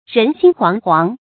人心惶惶 rén xīn huáng huáng 成语解释 惶惶：惊惧不安的样子。
成语简拼 rxhh 成语注音 ㄖㄣˊ ㄒㄧㄣ ㄏㄨㄤˊ ㄏㄨㄤˊ 常用程度 常用成语 感情色彩 贬义成语 成语用法 主谓式；作谓语、定语、补语、分句；含贬义 成语结构 主谓式成语 产生年代 古代成语 成语正音 惶，不能读作“huānɡ”。